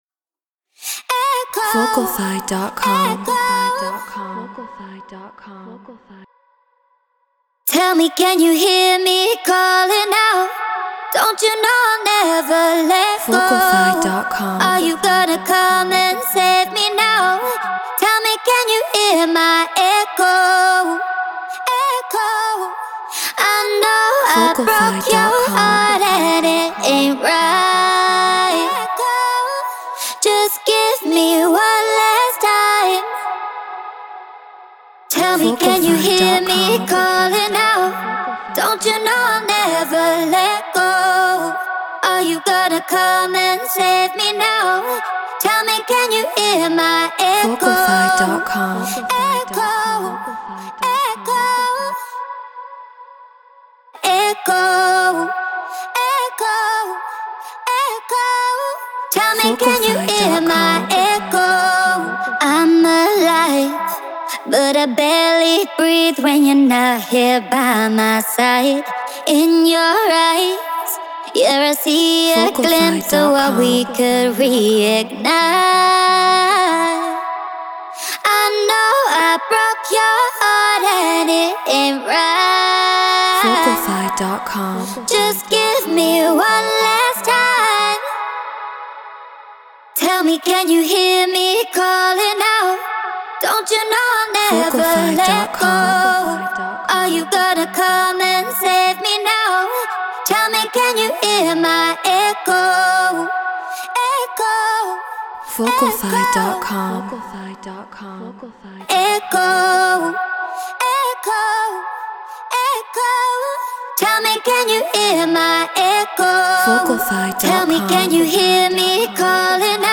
UKG 140 BPM F#min
Human-Made